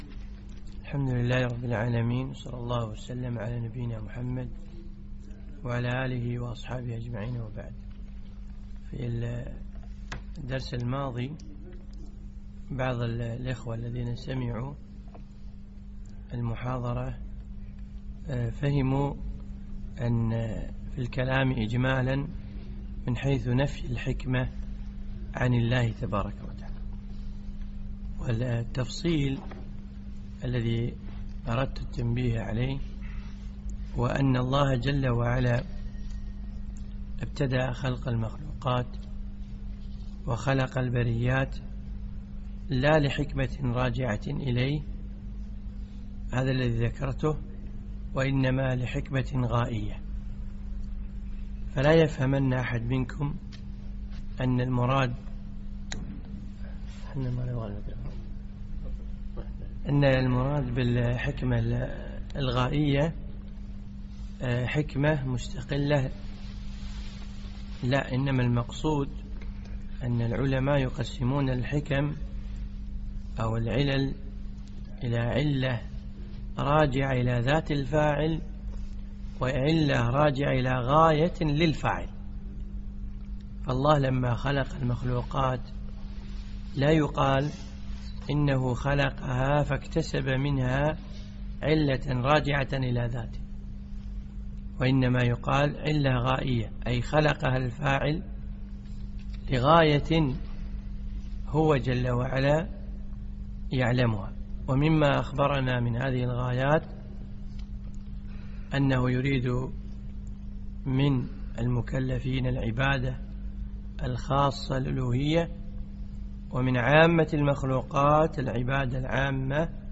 من دروس الشيخ في دولة الإمارات
الدرس الرابع عشر